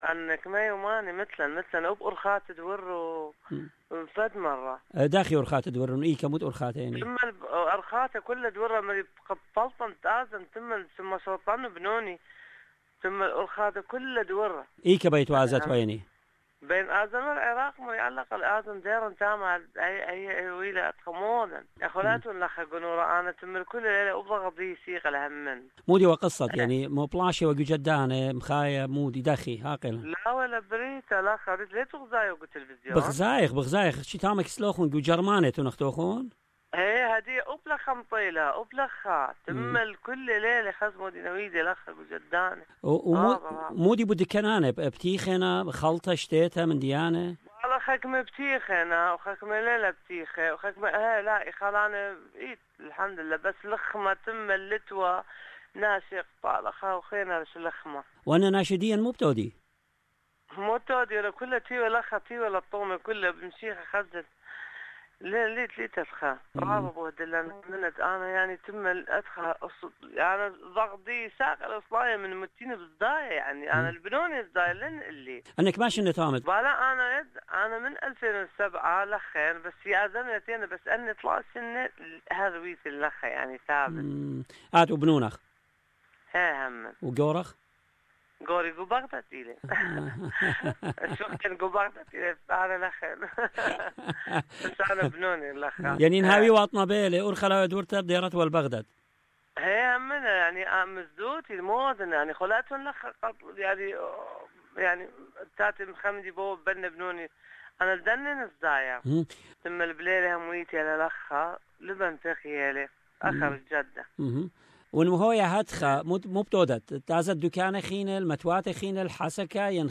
Host: